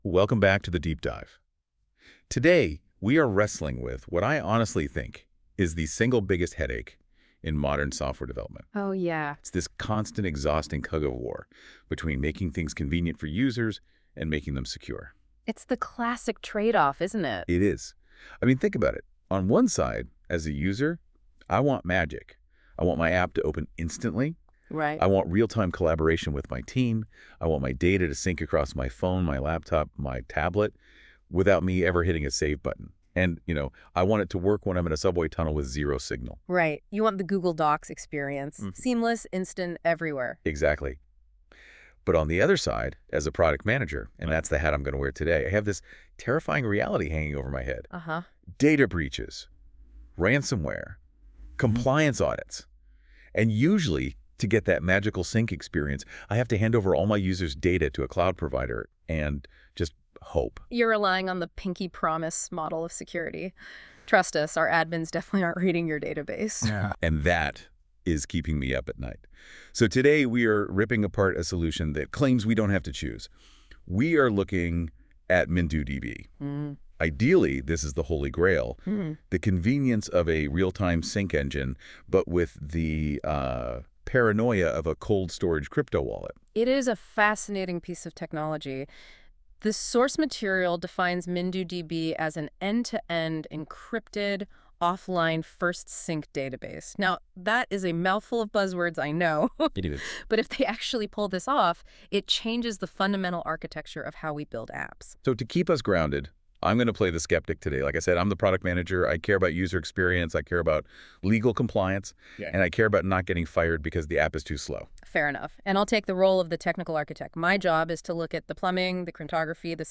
Two AI hosts discuss how MindooDB handles encryption, offline collaboration, and sync — without ever trusting the server. A relaxed deep-dive into the architecture.